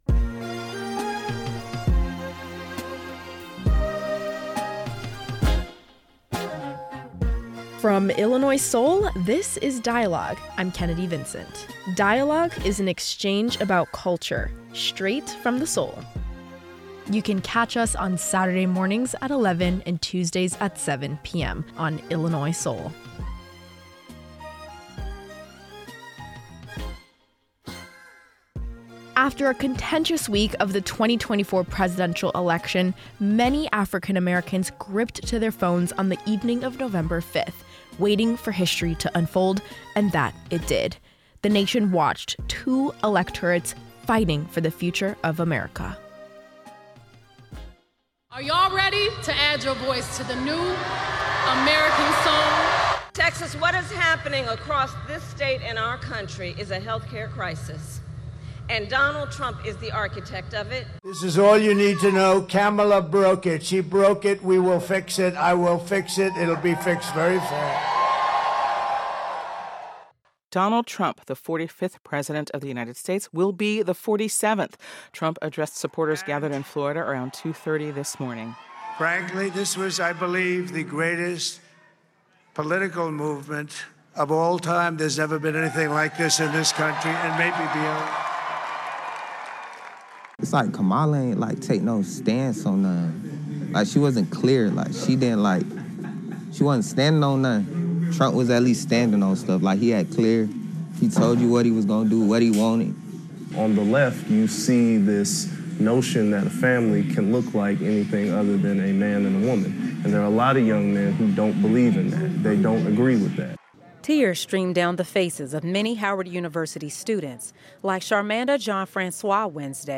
Today, Dialogue hears from experts on the election loss and its effect on Black Americans’ mental health.